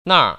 [nàr] 나알  ▶